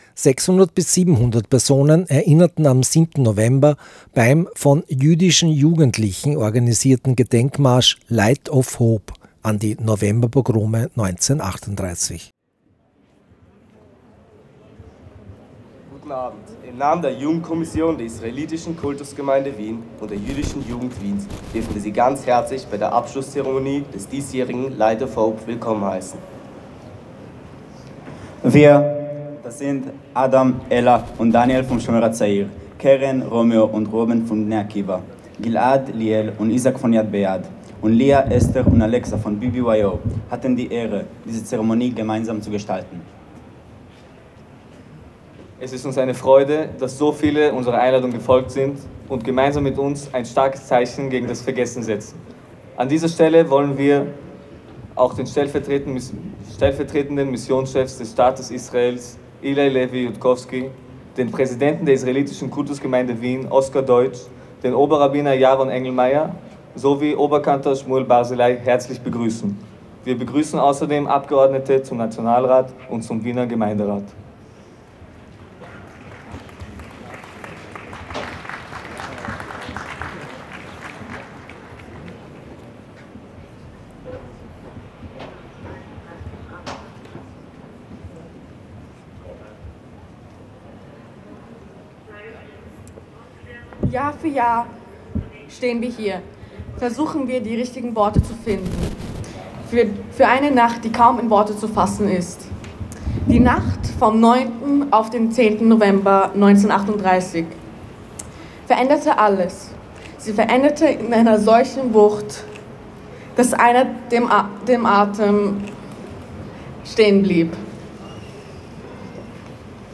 Shownotes 600–700 Personen erinnerten am 7. November 2024 beim von jüdischen Jugendlichen organisierten Gedenkmarsch „Light of Hope“ an die Novemberpogrome 1938. Der Marsch führte heuer wieder vom Heldenplatz zum Judenplatz. Vollständige Aufnahme der Abschlusskundgebung am Judenplatz.